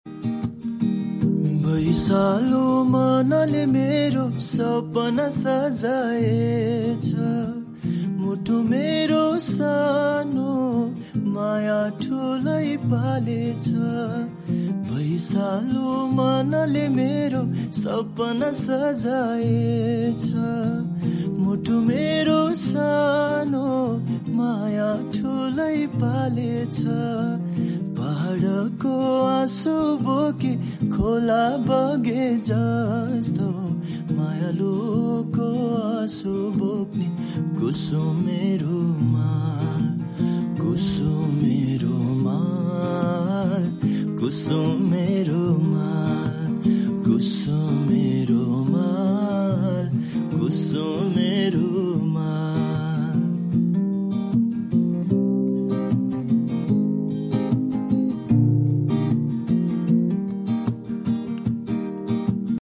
raw cover song